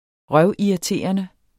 Udtale [ ˈʁɶw- ]